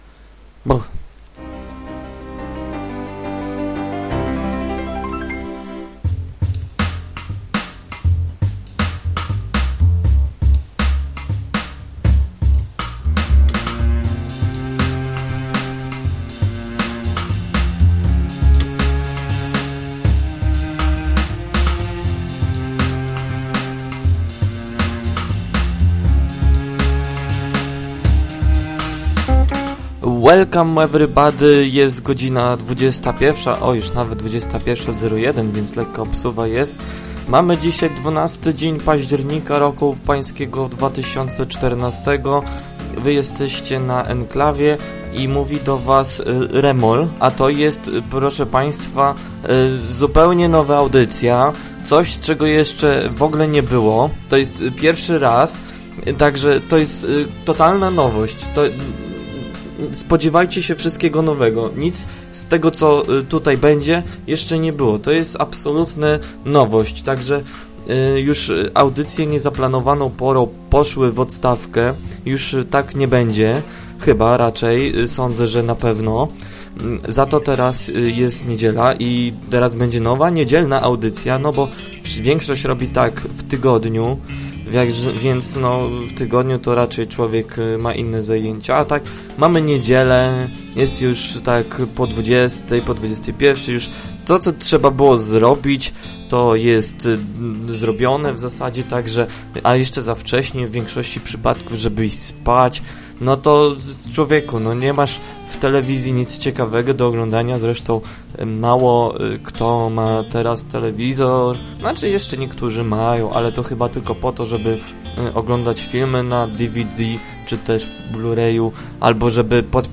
Fajna audycja, podziwiam że tak długo jesteś w stanie monologować.